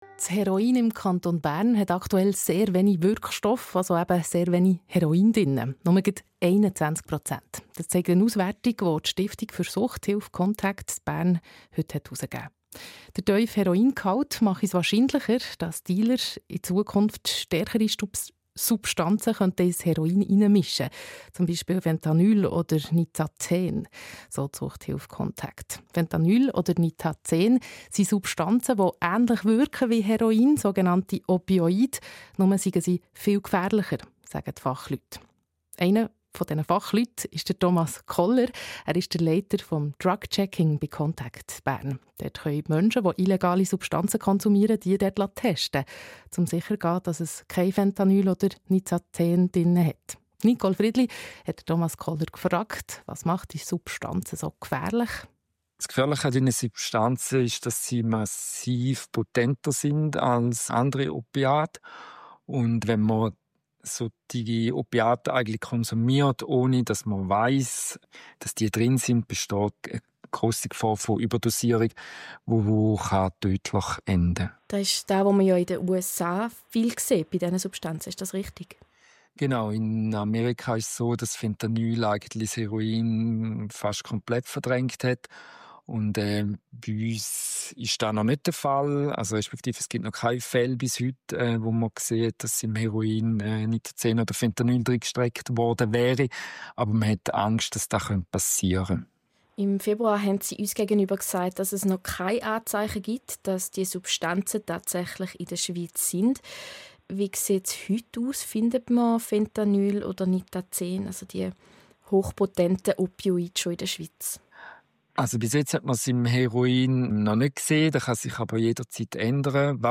SRF Regionaljournal: Interview über Kokainkonsum